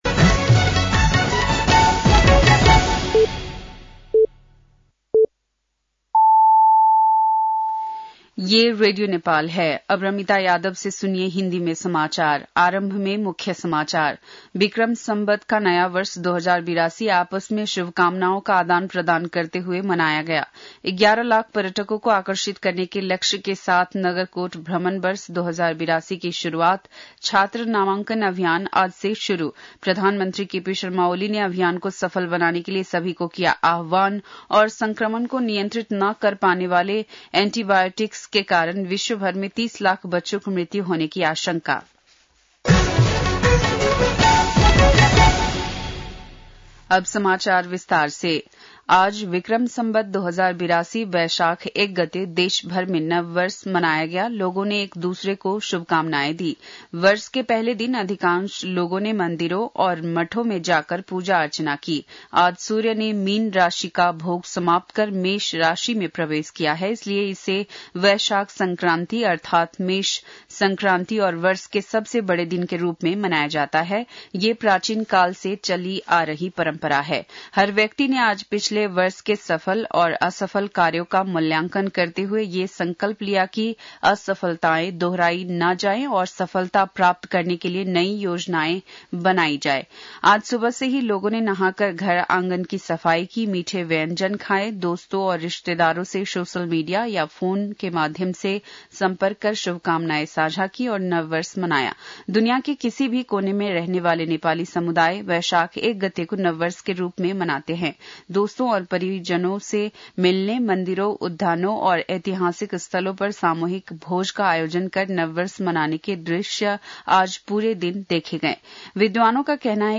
बेलुकी १० बजेको हिन्दी समाचार : १ वैशाख , २०८२